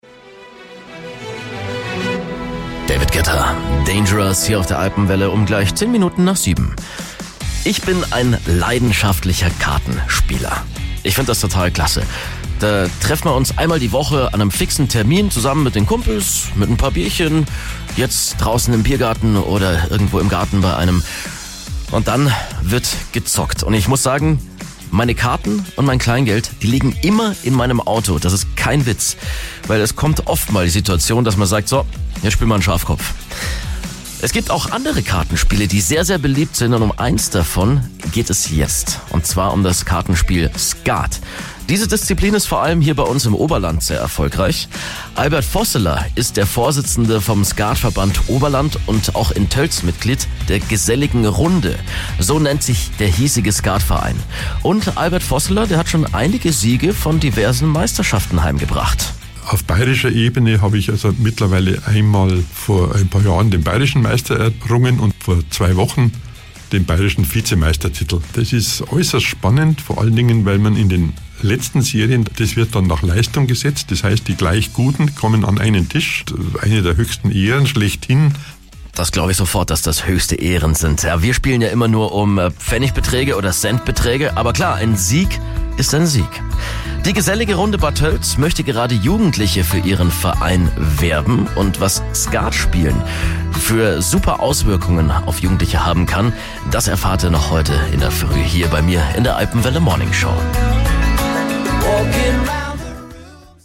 Morgens um 6.10 Uhr ging es los mit den jeweils knapp 2-minütigen Blöcken, die aus dem nahezu einstündigen Interview (siehe auch unten!) zusammengeschnitten wurden.